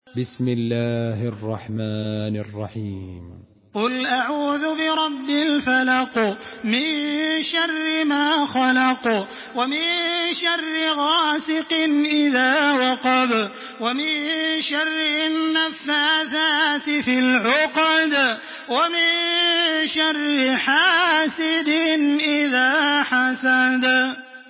تحميل سورة الفلق بصوت تراويح الحرم المكي 1426
مرتل